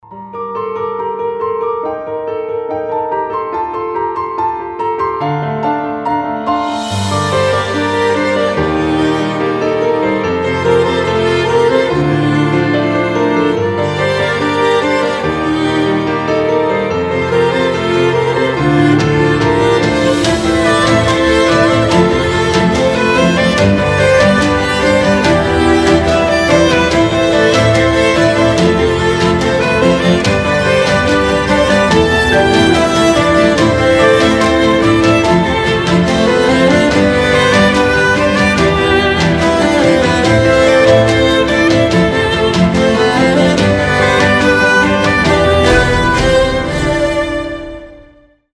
• Качество: 320, Stereo
красивые
мелодичные
без слов
красивая мелодия
нарастающие
скрипка
инструментальные
пианино
оркестр
фортепиано
New Age